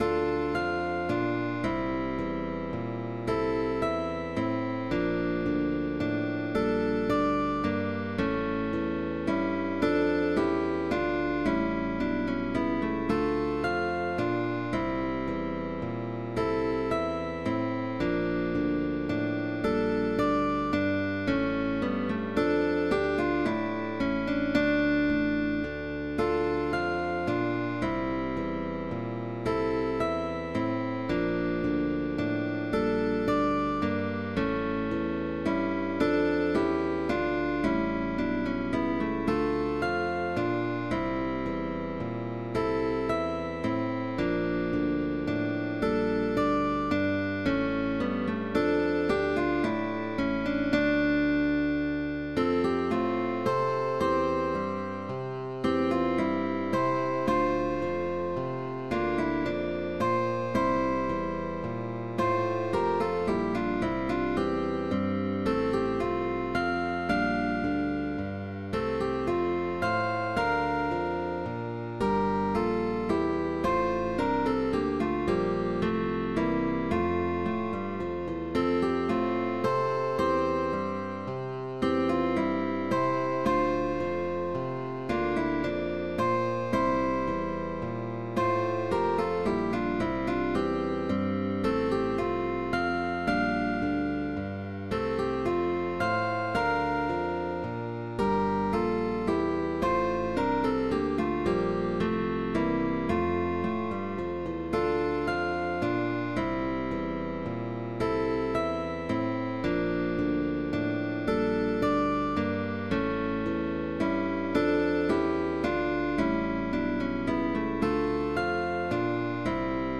TRIO DE GUITARRAS
Válido para orquesta de guitarras, con bajo opcional